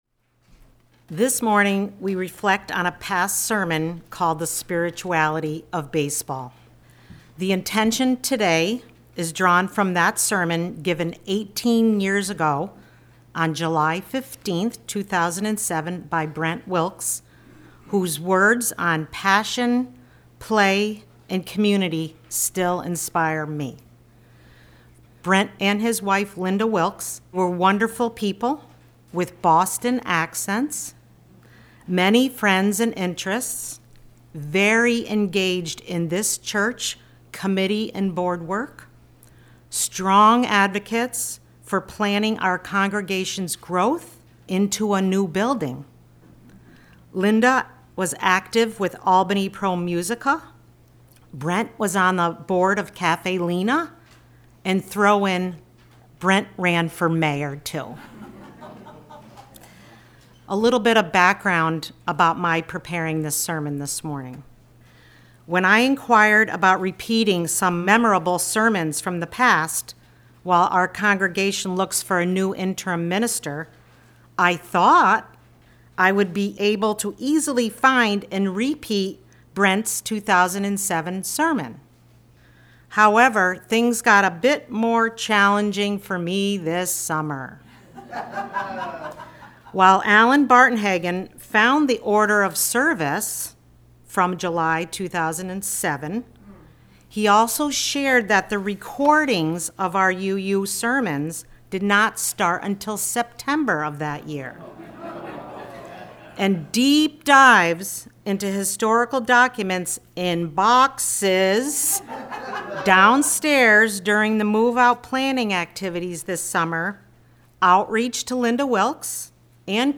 This morning we present a humorous perspective on community spirit, teamwork, and rooting for the home team.